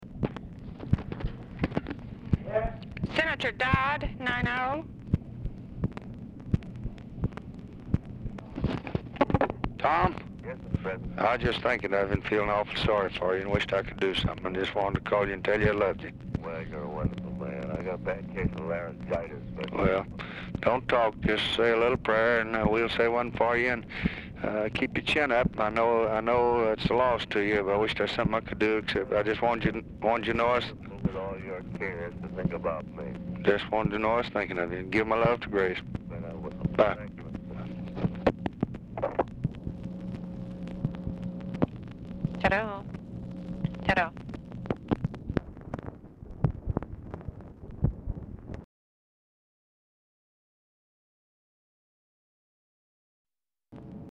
Format Dictation belt
Specific Item Type Telephone conversation Subject Condolences And Greetings Congressional Relations